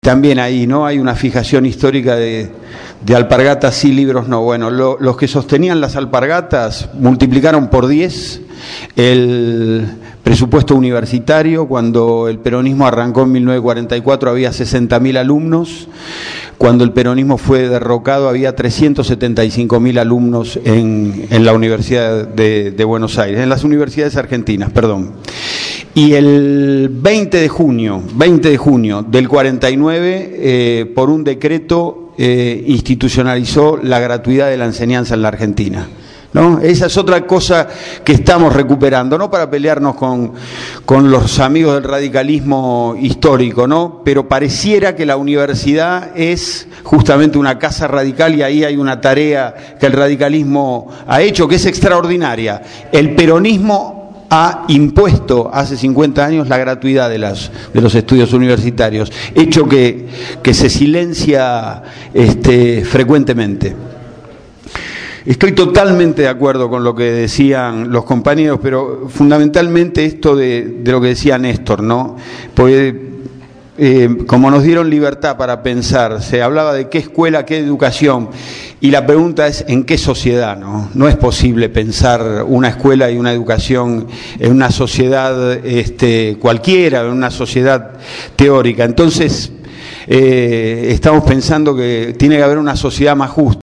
Organizado por Radio Gráfica y el programa «Cambio y Futuro», el último 19 de Mayo se realizó en la instalaciones de la radio la charla debate «Educación en el Bicentenario«.
Ante un centenar de presentes, la expectativa era mucha porque el panel de invitados así lo proponía: